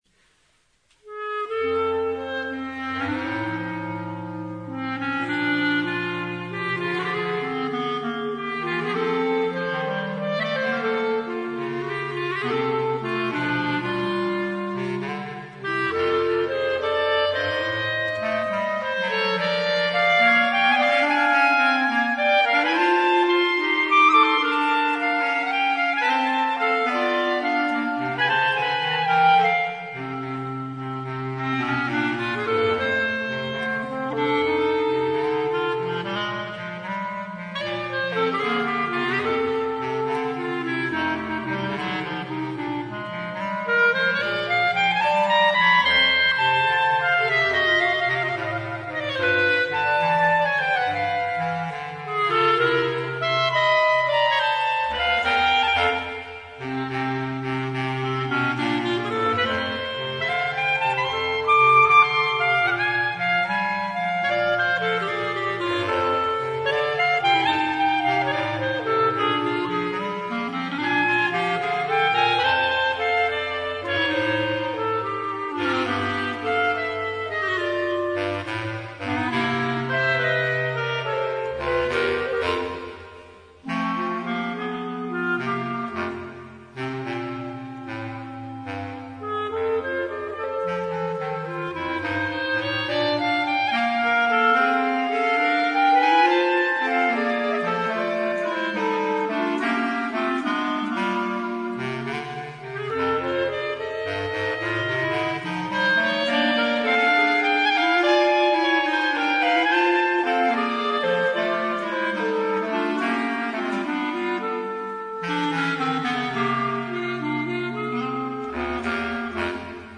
Per due clarinetti e clarinetto basso
Un blues per 2 clarinetti e clarinetto basso.